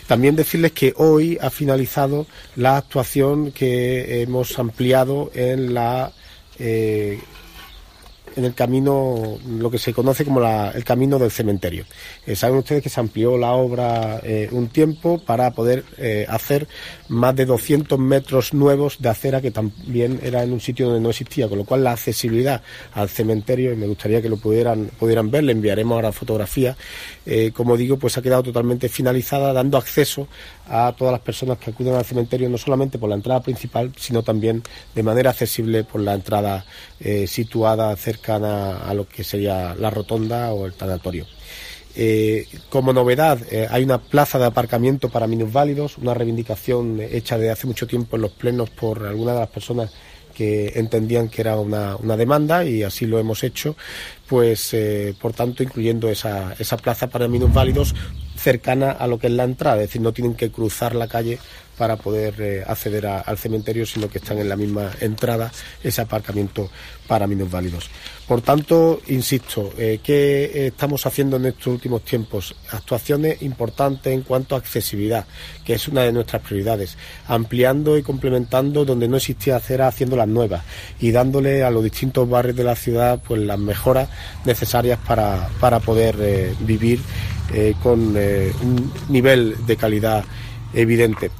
Cortes de voz
J.R. Carmona   625.36 kb  Formato:  mp3